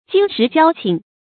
金石交情 注音： ㄐㄧㄣ ㄕㄧˊ ㄐㄧㄠ ㄑㄧㄥˊ 讀音讀法： 意思解釋： 見「金石之交」。